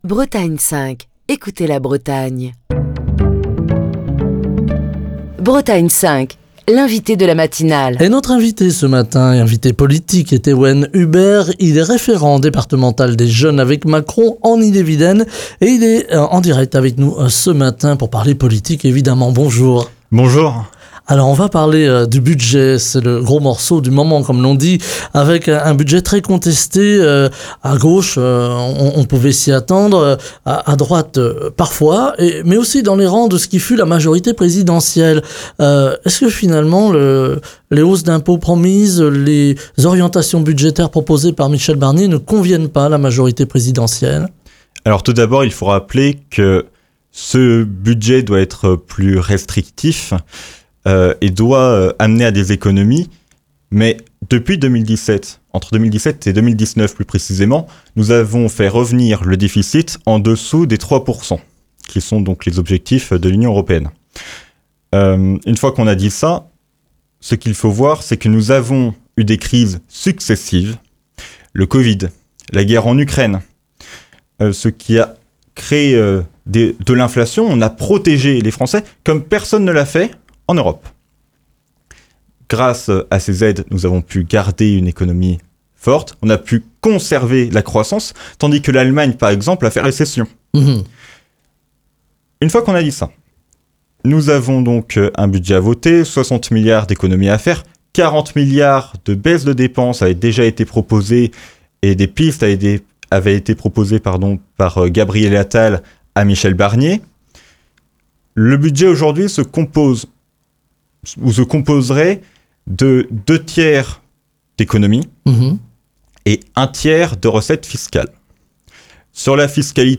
Émission du 15 octobre 2024.